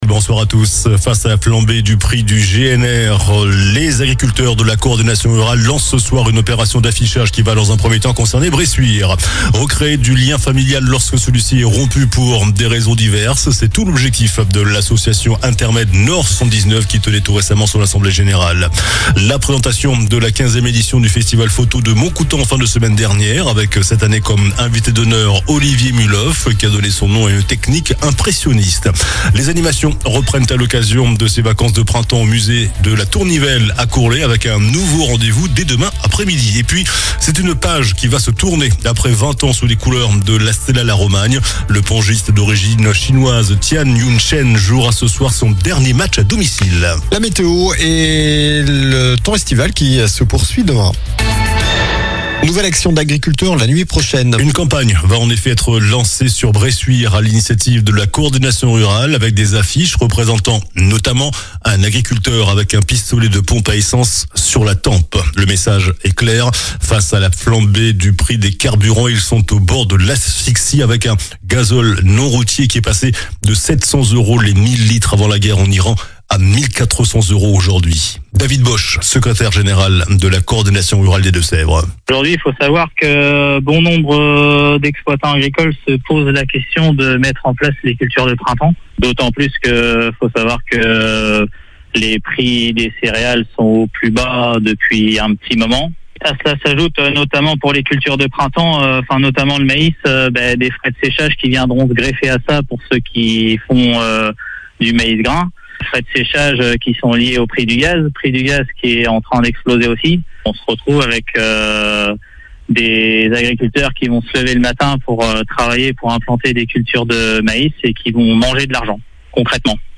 JOURNAL DU MARDI 07 AVRIL ( SOIR )